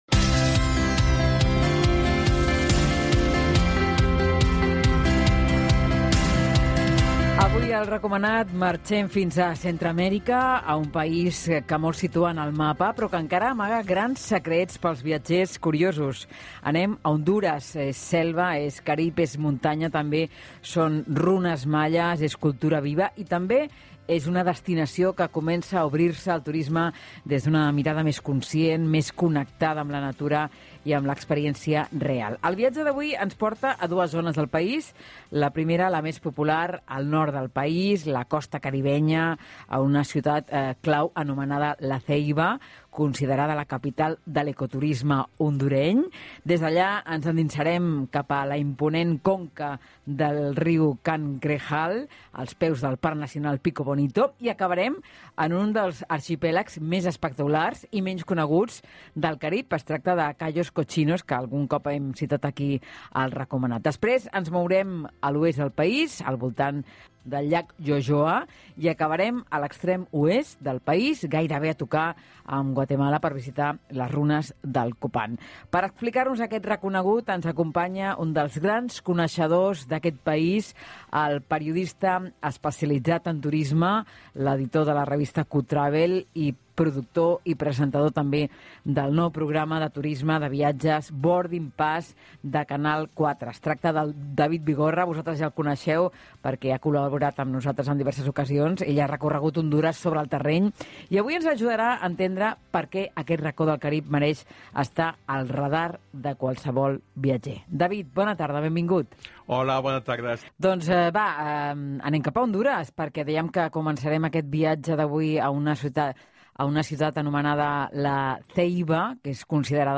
Revive mi paso por RAC1manat para descubrir la Honduras más auténtica. Un recorrido desde el Caribe de La Ceiba y Cayos Cochinos hasta el misticismo maya de Copán.